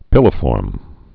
(pĭlə-fôrm)